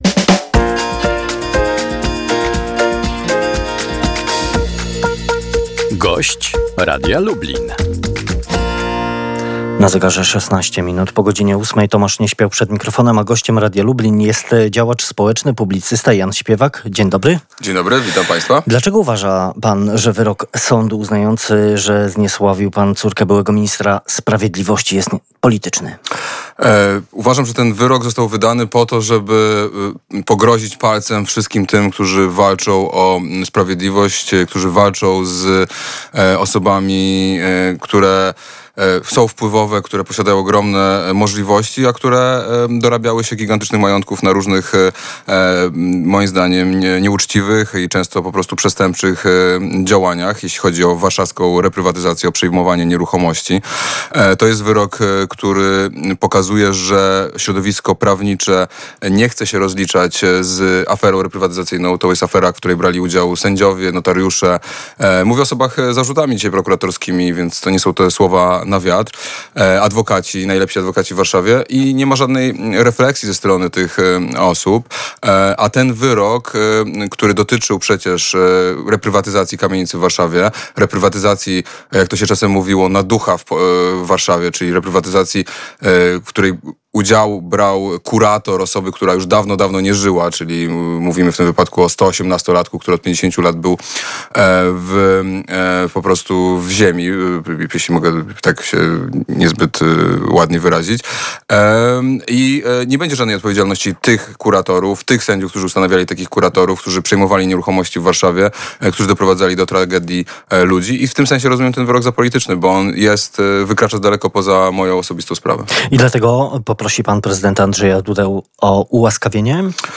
– Uważam, że ten wyrok został wydany po to, żeby pogrozić palcem wszystkim, którzy walczą o sprawiedliwość, walczą z wpływowymi osobami, które posiadają ogromne możliwości, a które dorabiały się gigantycznych majątków na różnych, moim zdaniem, nieuczciwych i często przestępczych działaniach jeśli chodzi o warszawską reprywatyzację, o przejmowanie nieruchomości – mówił Jan Śpiewak, który był gościem porannej rozmowy w Polskim Radiu Lublin: – To jest wyrok, który pokazuje, że środowisko prawnicze nie chce się rozliczać z aferą reprywatyzacyjną.